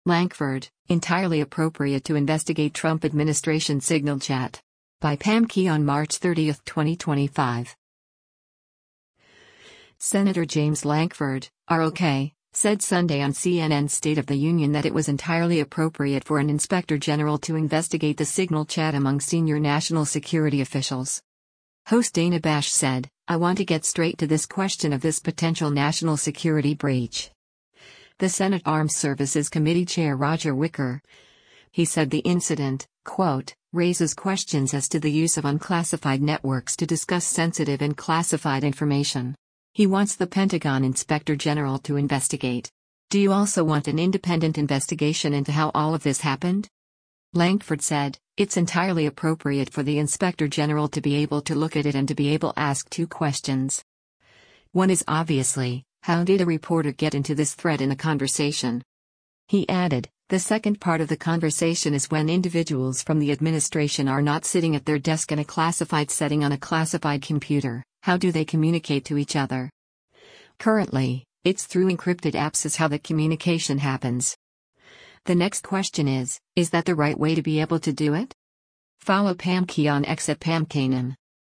Senator James Lankford (R-OK) said Sunday on CNN’s “State of the Union” that it was “entirely appropriate” for an inspector general to investigate the Signal chat among senior national security officials.